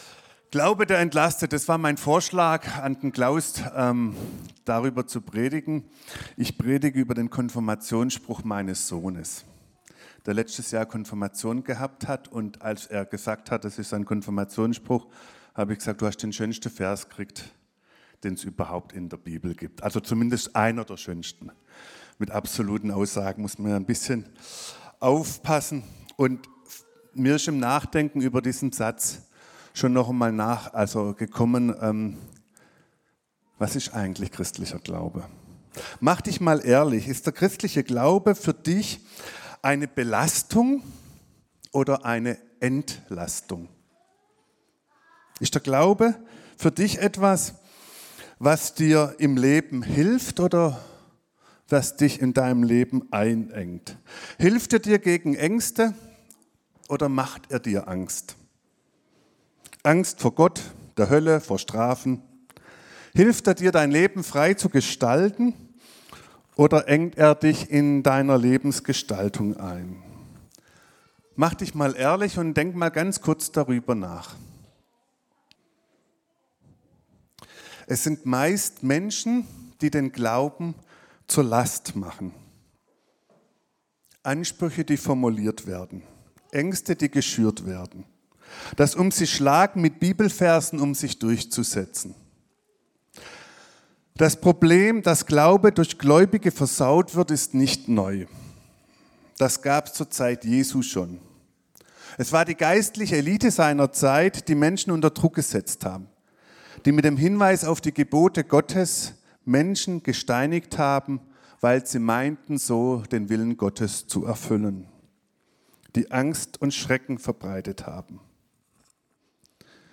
Ein Glaube der entlastet ~ Predigten - Gottesdienst mal anders Podcast